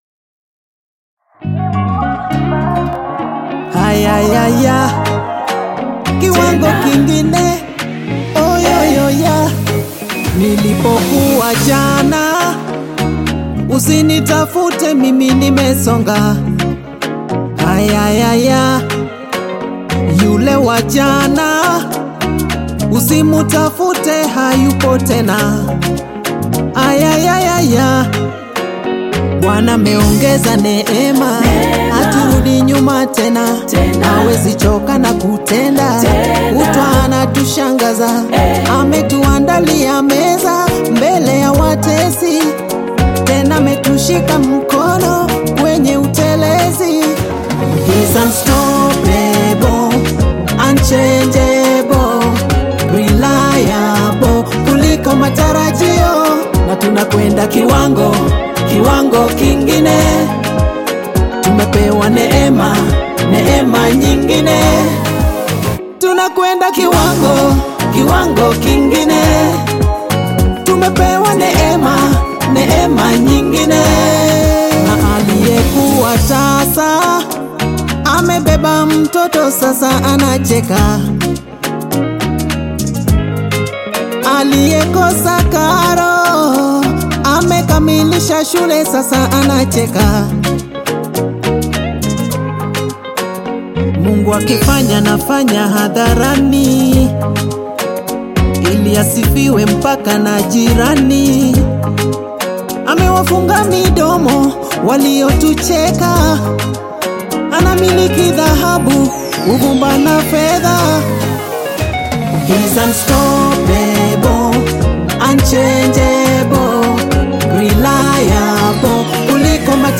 AudioGospelNyimbo za Dini